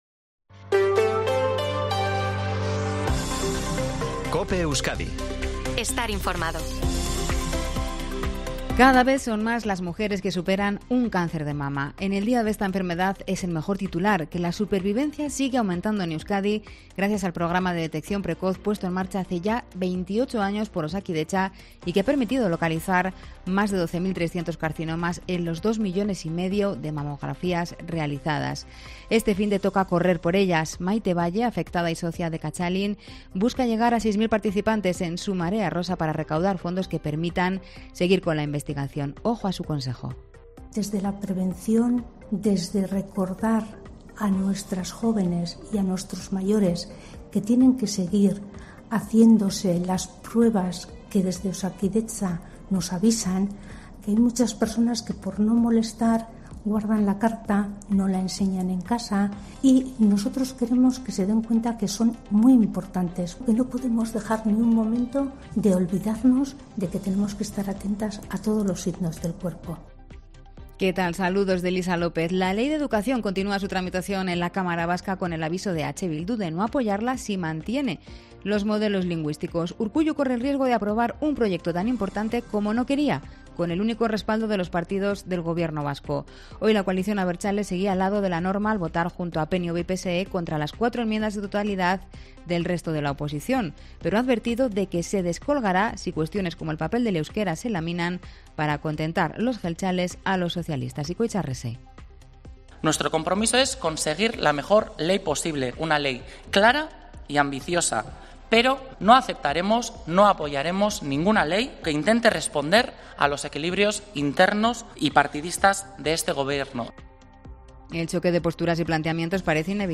INFORMATIVO TARDE COPE EUSKADI 20/10/2023